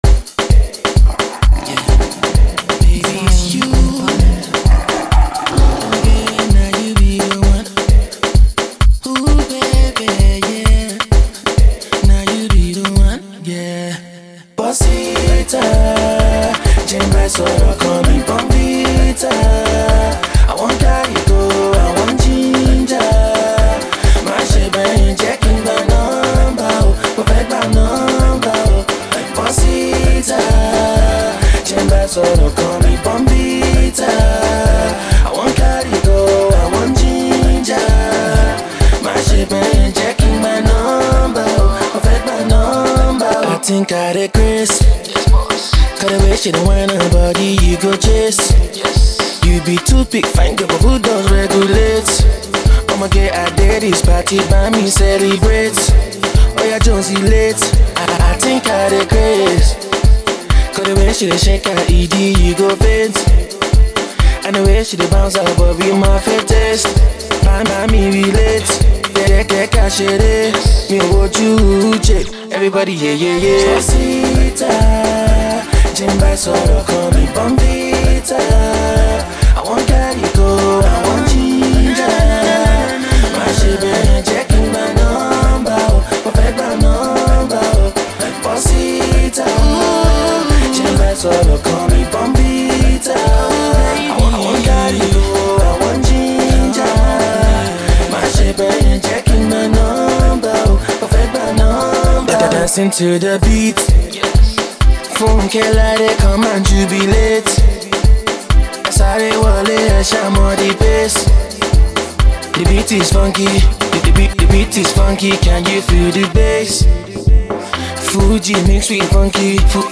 new wave – new skool, new sound of Afro-Pop/Dance
catchy, uptempo and laid-back at the same time